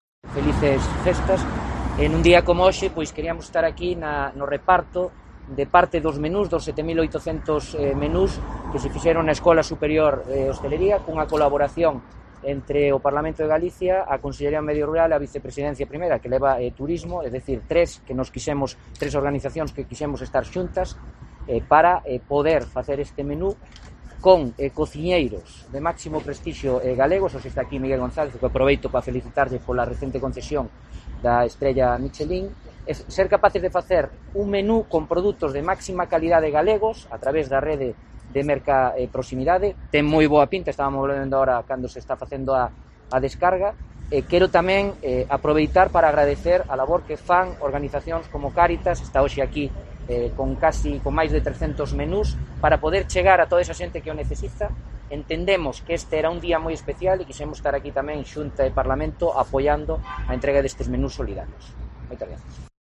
Declaraciones del conselleiro de Medio Rural, José González, en la entrega de los menús a Cáritas Ourense